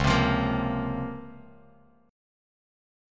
C#M13 chord